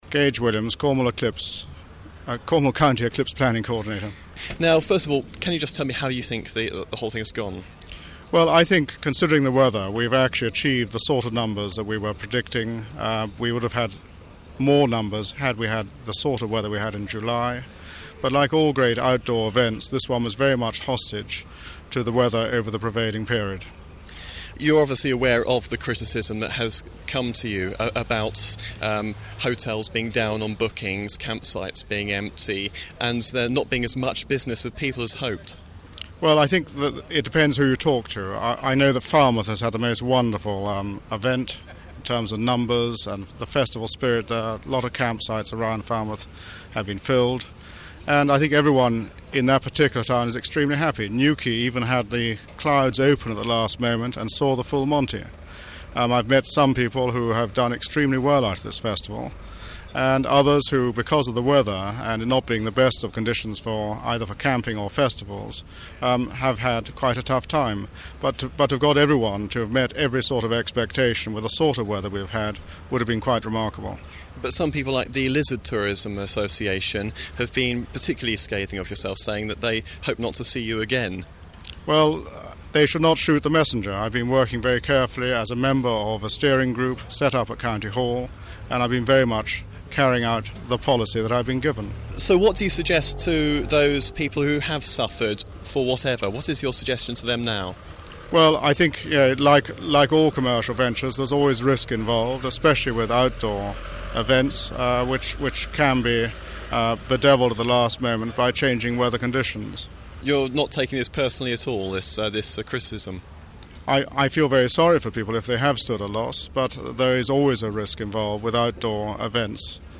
I interviewed him after the event.